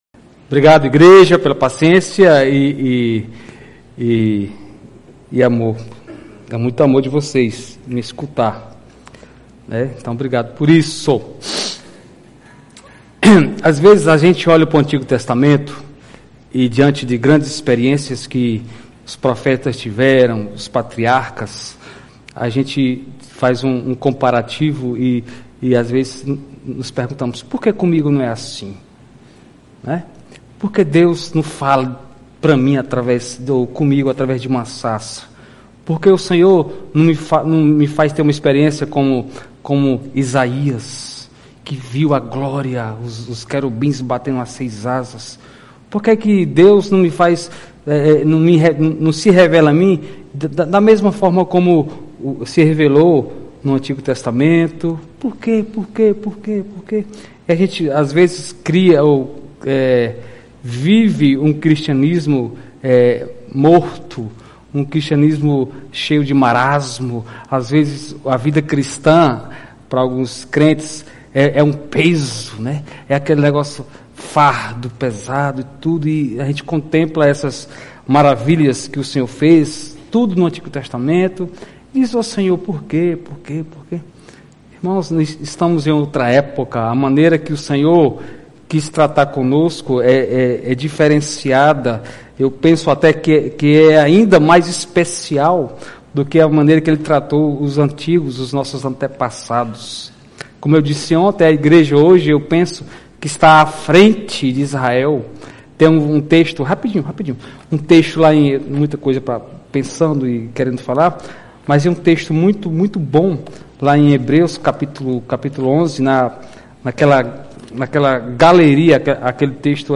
Igreja Batista Luz do Mundo, Fortaleza/CE.
Pregação